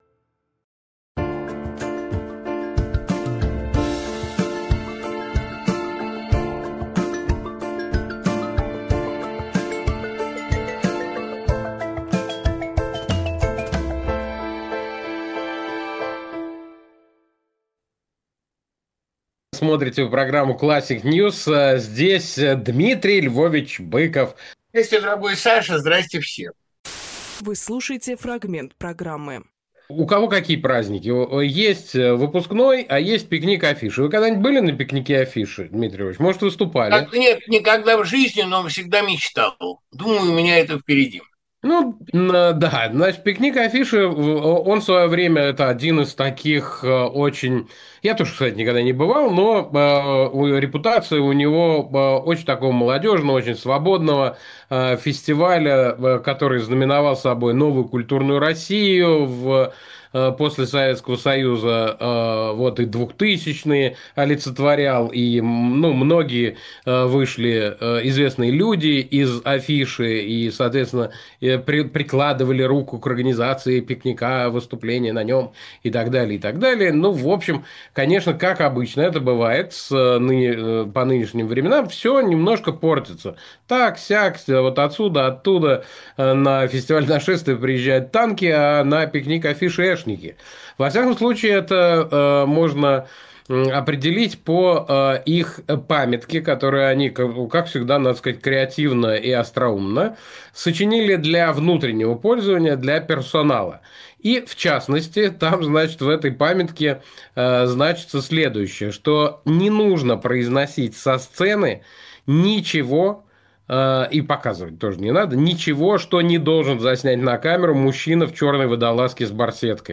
Дмитрий Быковпоэт, писатель, журналист
Фрагмент эфира от 29.06.25